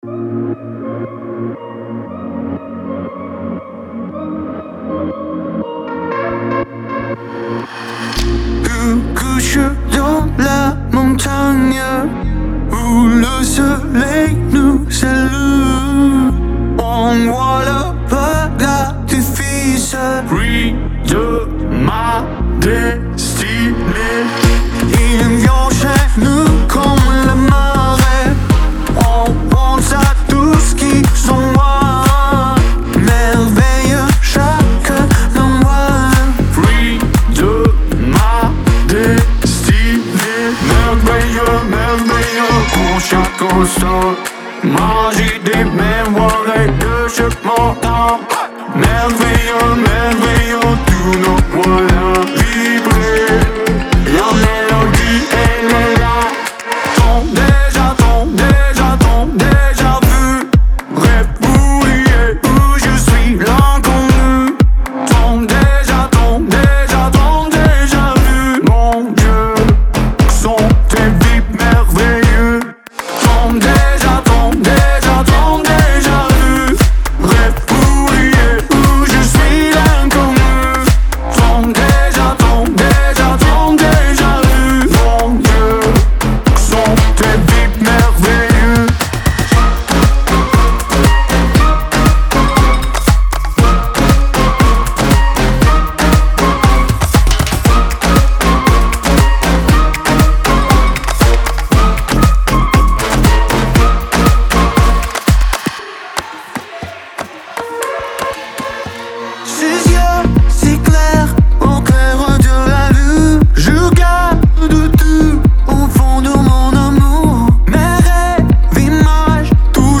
выполненная в жанре поп с элементами электронной музыки.